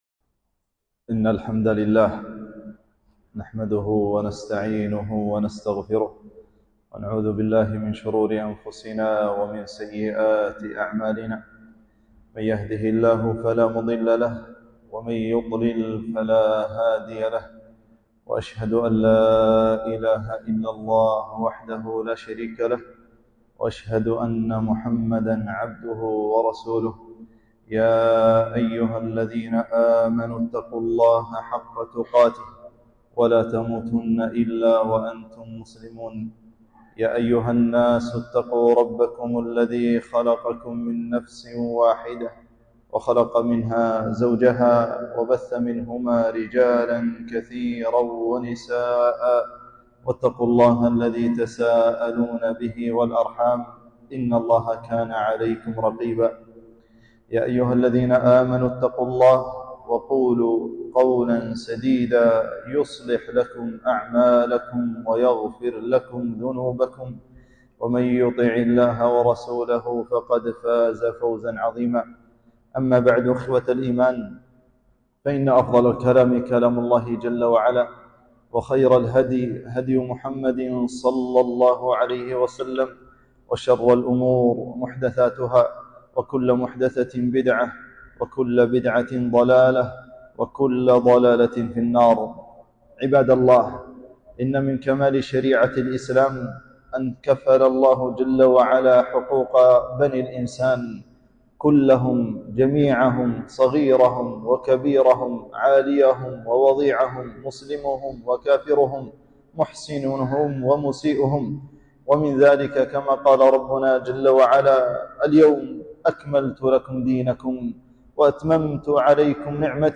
خطبة - تعظيم شأن الجيران